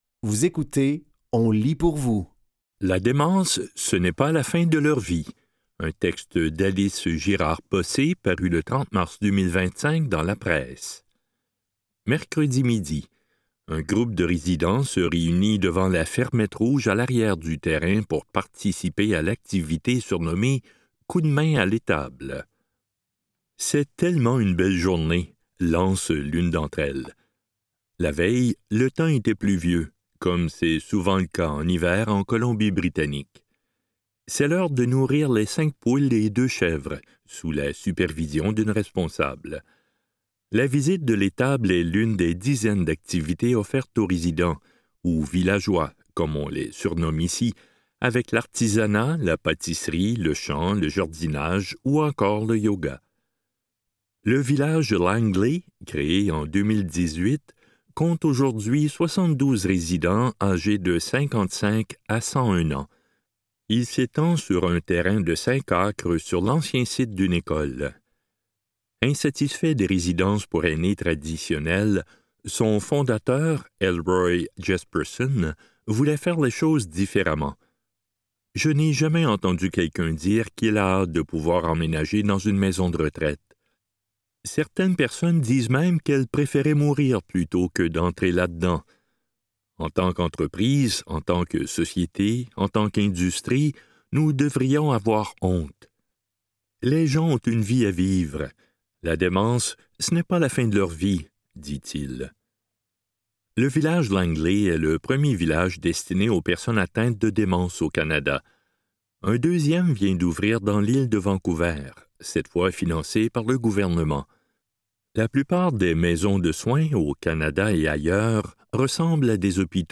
Dans cet épisode de On lit pour vous, nous vous offrons une sélection de textes tirés des médias suivants : La Presse, Info de Québec, Le Nouvelliste, Le Journal de Montréal, Lanauweb.